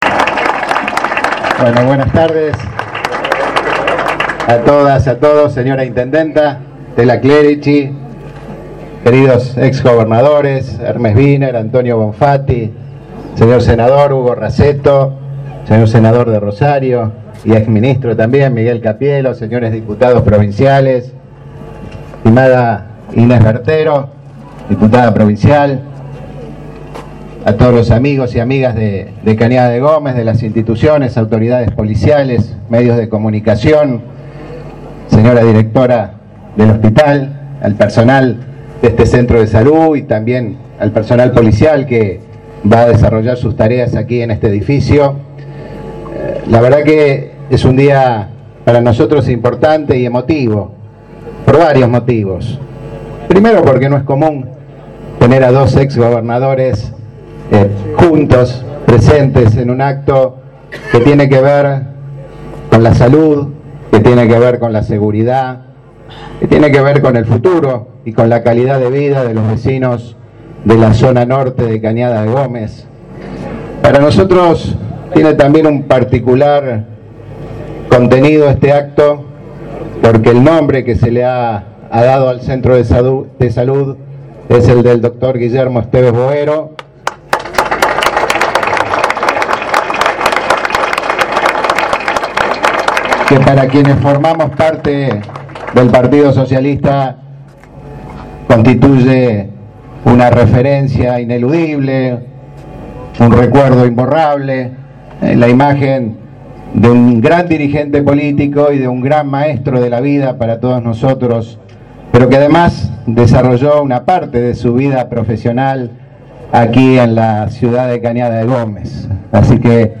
El gobernador Lifschitz, durante la inauguración del nuevo centro de salud y la sede del Comando Ra